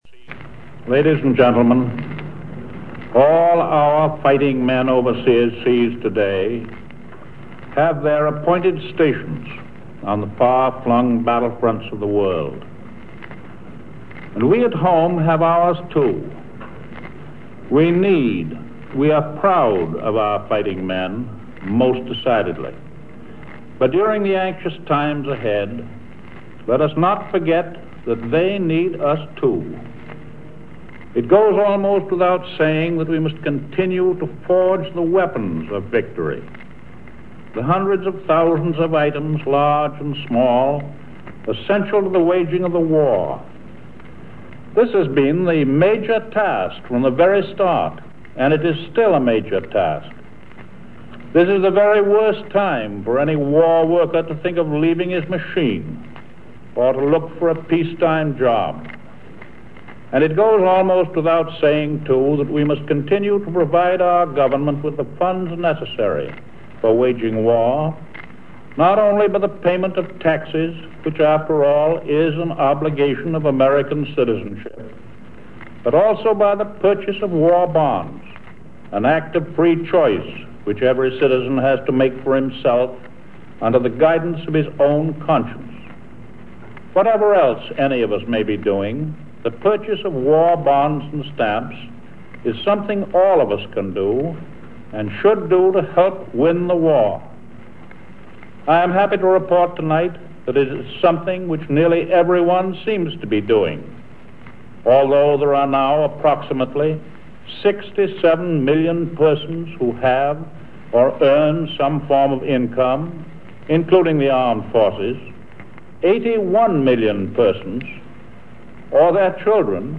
The last fireside chat by President Roosevelt, intended to promote the opening of the fifth war loan drive.